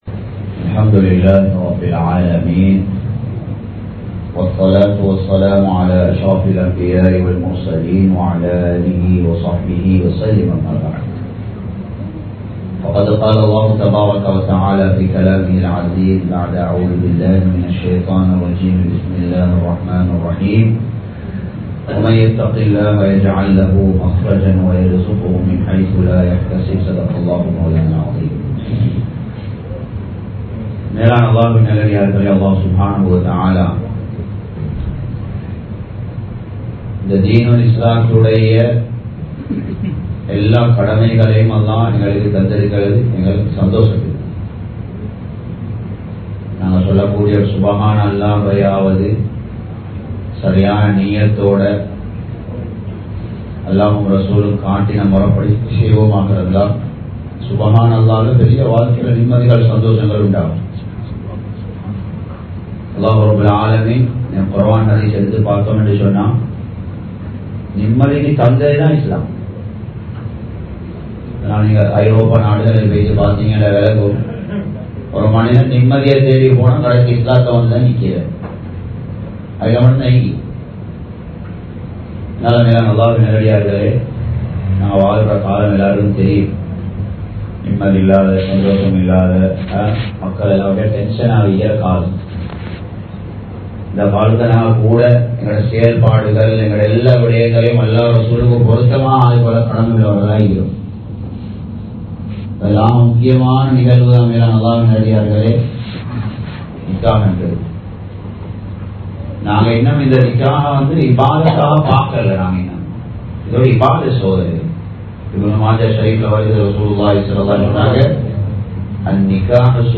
திருமணம் ஒரு இபாதத் | Audio Bayans | All Ceylon Muslim Youth Community | Addalaichenai